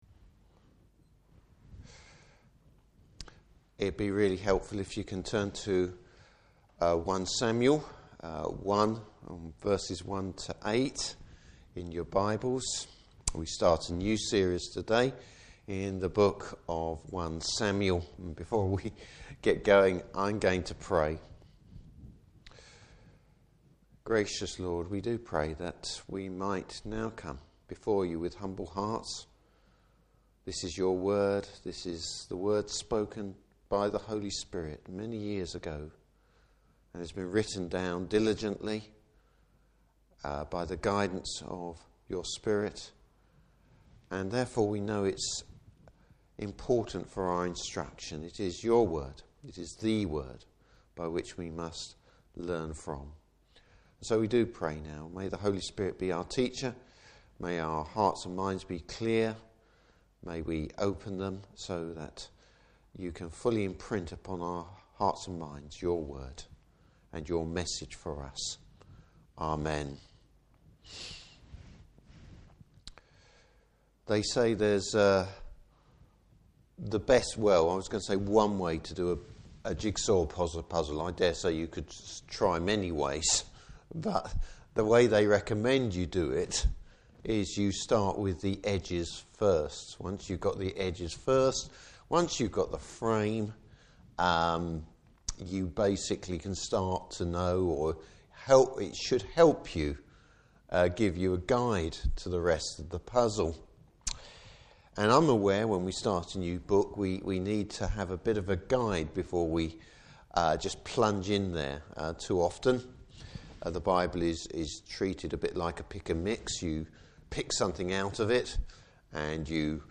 Service Type: Evening Service How the writer’s focus on one family demonstrates the major problems of God’s people.